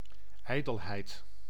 Ääntäminen
Ääntäminen France: IPA: [ɔʁ.ɡœj]